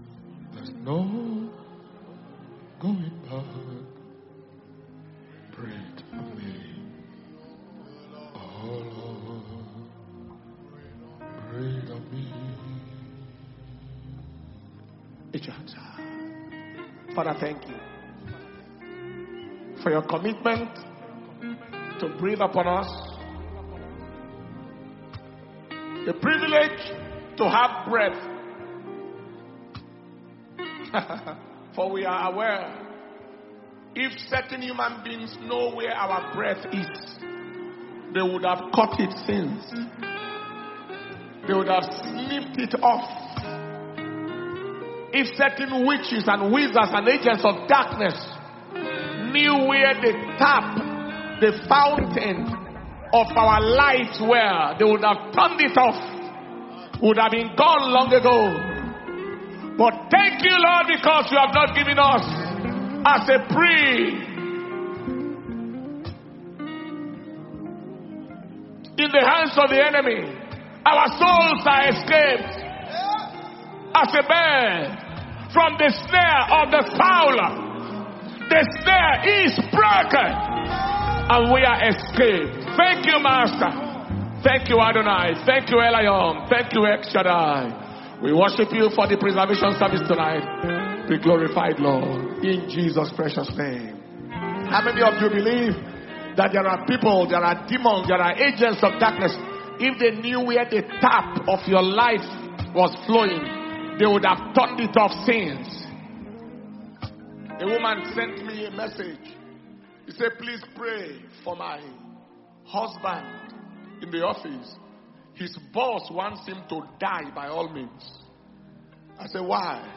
April 2025 Preservation And Power Communion Service